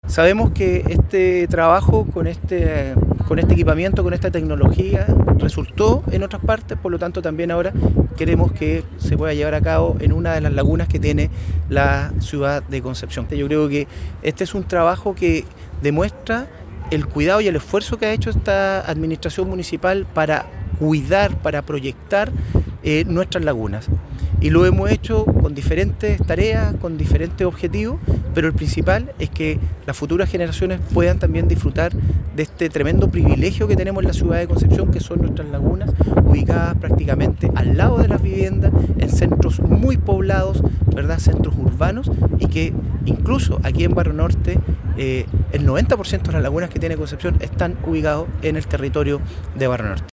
Al respecto, el alcalde Álvaro Ortiz Vera destacó el esfuerzo municipal por proteger las lagunas de Concepción, señalando que el objetivo principal es que “las futuras generaciones puedan disfrutar de este privilegio que tenemos en la ciudad de Concepción, que son nuestras lagunas”.